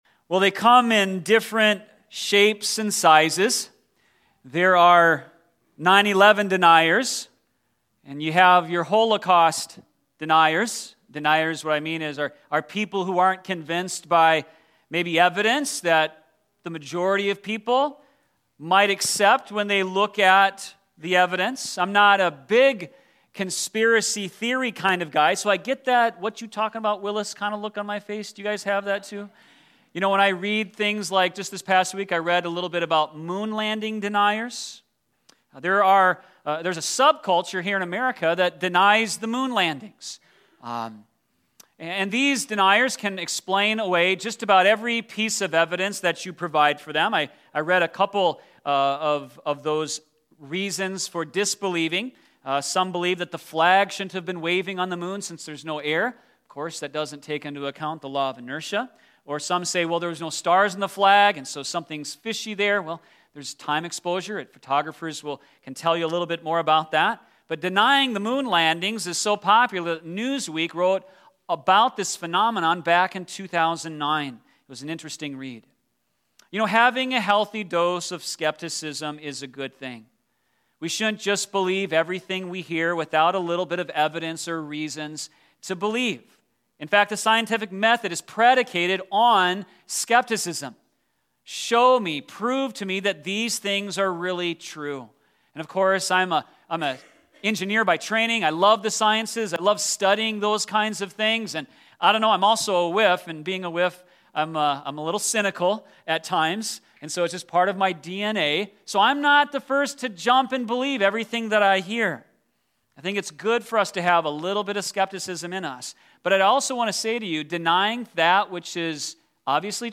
Sermon Reference: Dude Perfect Video